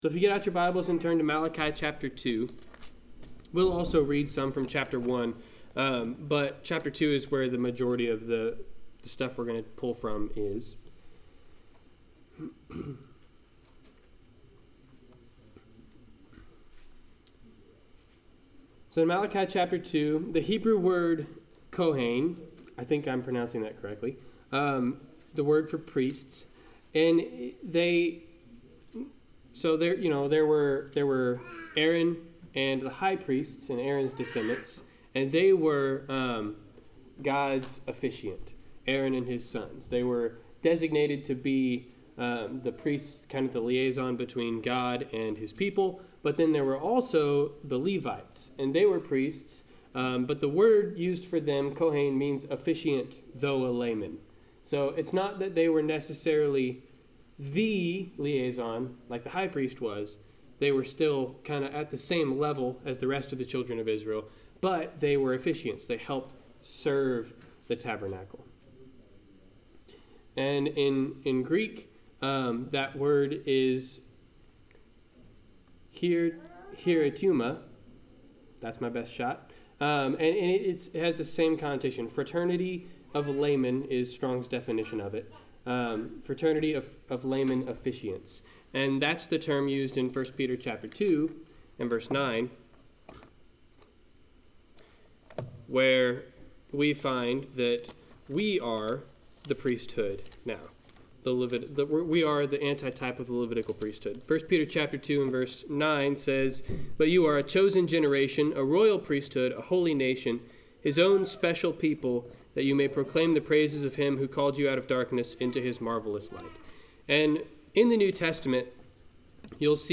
Passage: Malachi 1-2 Service Type: Sunday 10:00 AM